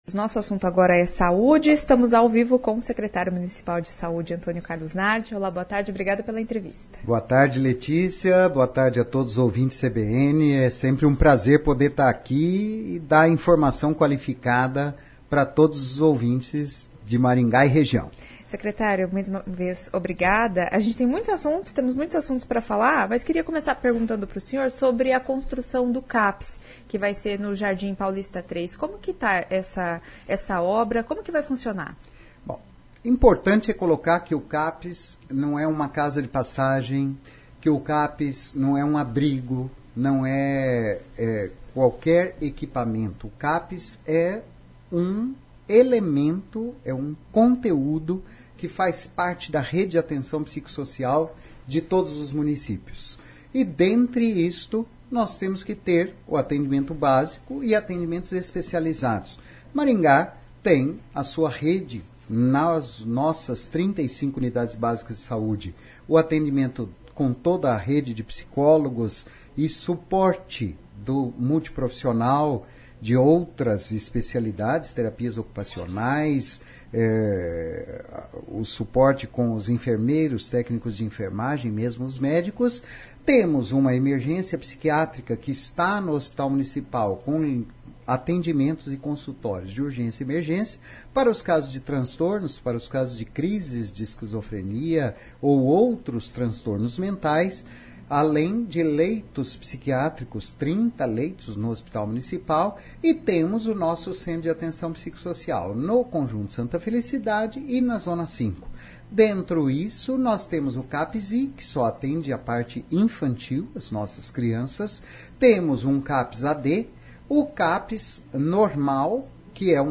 O secretário de Saude Carlos Nardi fala sobre às consultas especializadas em 2025.